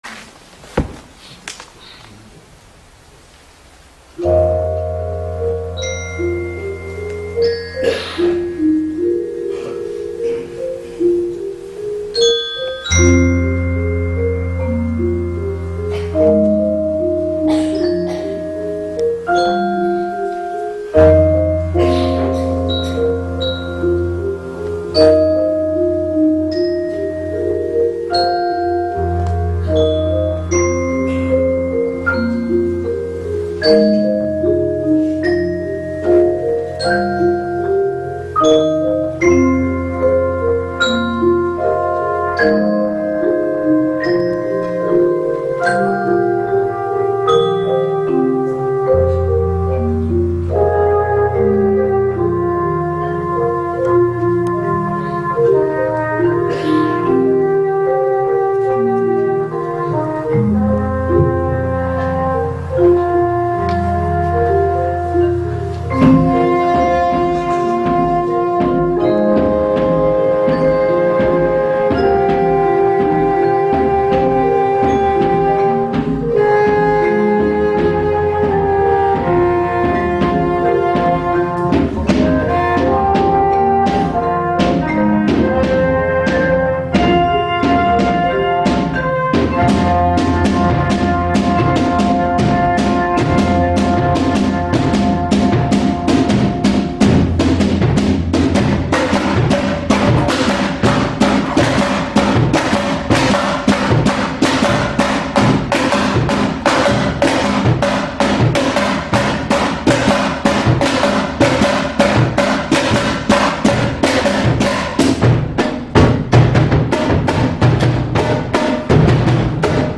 beluister hier een live uitvoering
slagwerkgroep